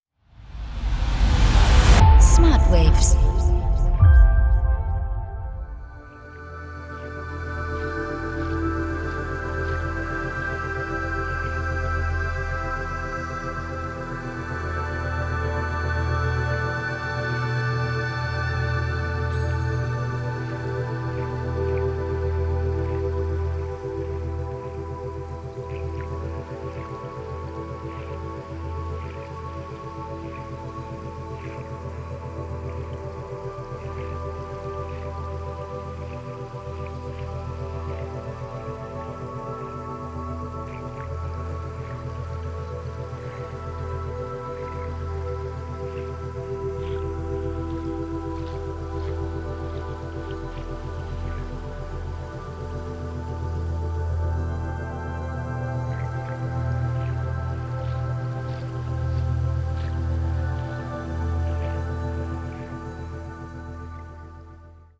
4-8 Hertz Theta Wellen Frequenzen